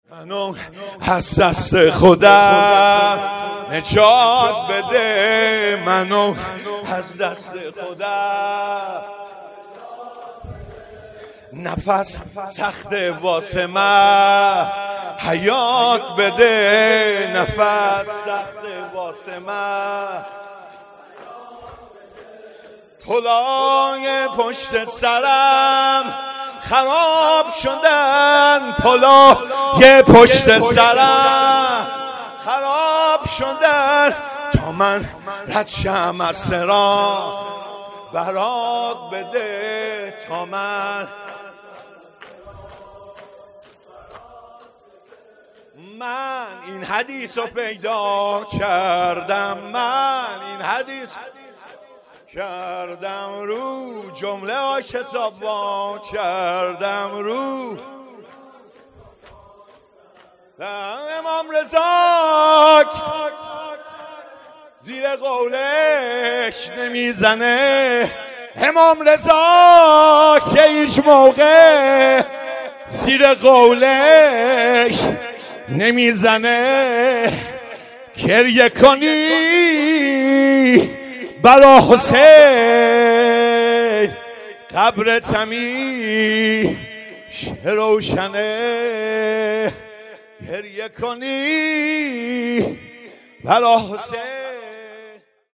شور
شب دوم مراسم عزاداری شهادت حضرت ام البنین سلام علیها ۱۴۰۲